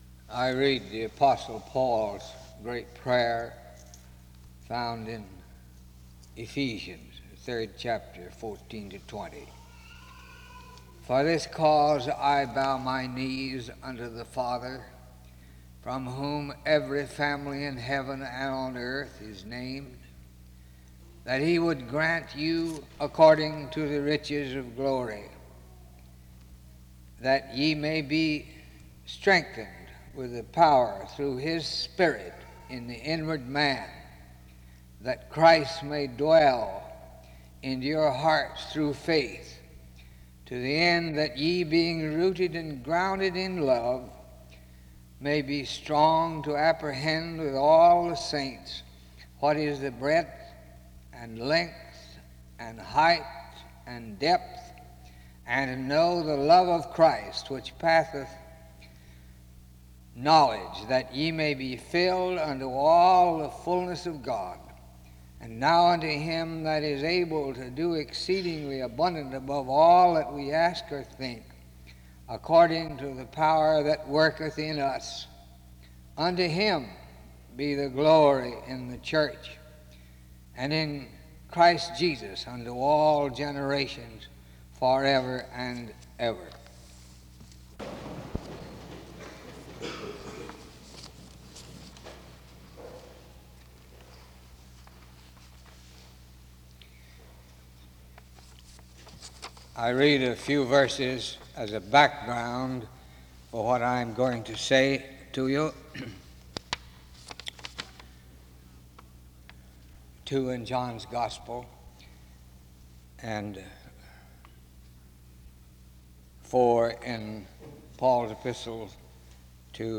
Em Collection: SEBTS Chapel and Special Event Recordings SEBTS Chapel and Special Event Recordings - 1960s